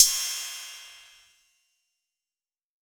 6RIDE BELL.wav